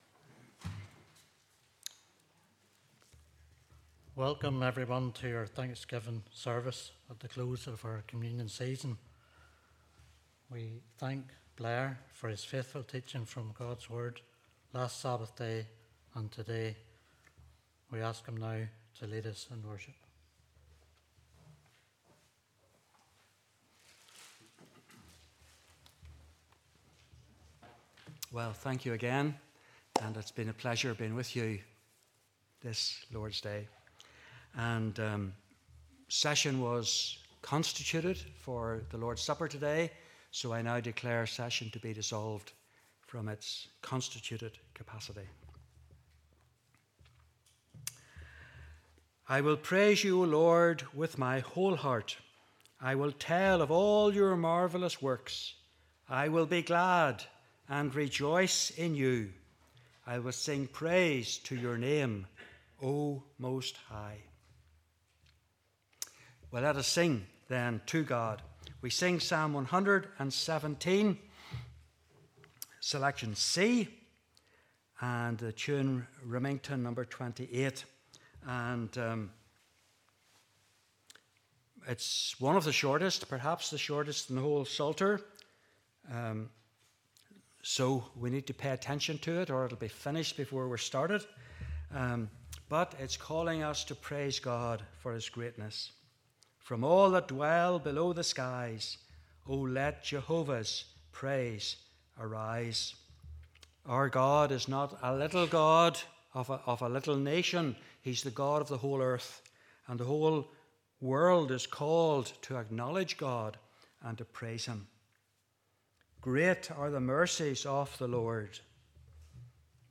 Thanks-giving Service